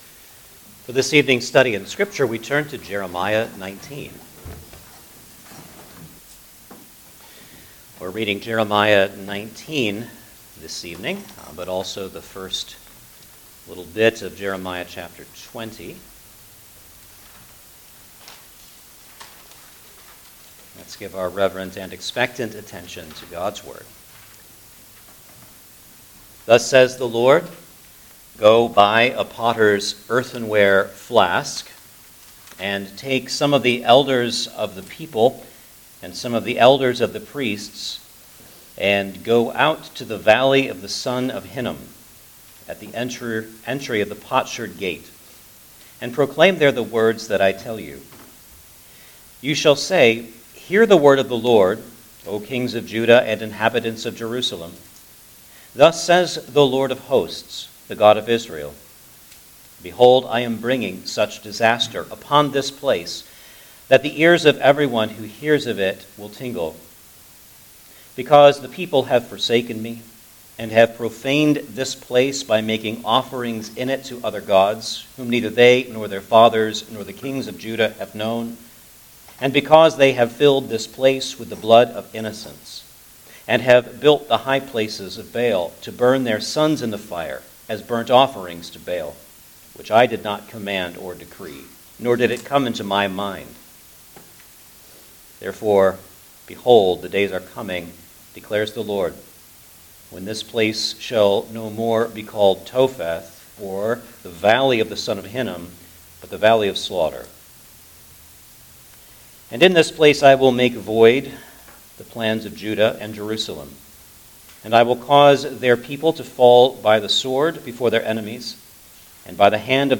Jeremiah Passage: Jeremiah 19:1 – 20:6 Service Type: Sunday Evening Service Download the order of worship here .